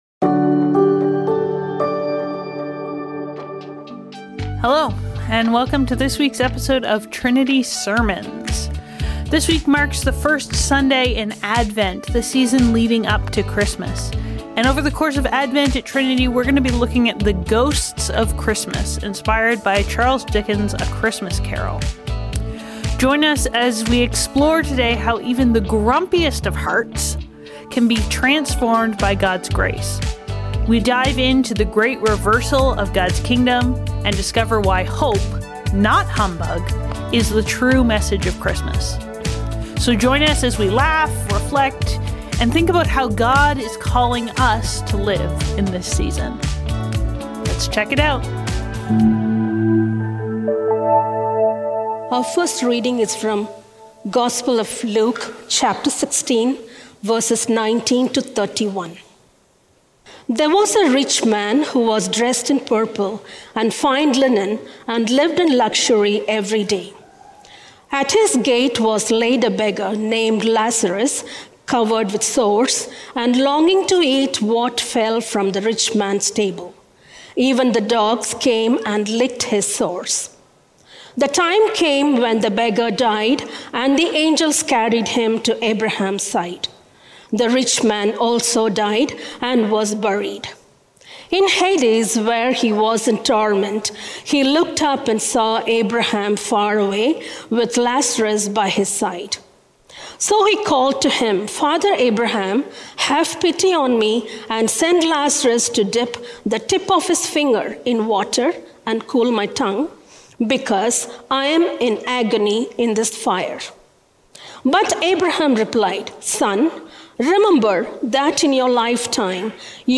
Trinity Streetsville - Bah Humbug | The Ghosts of Christmas | Trinity Sermons - Archive FM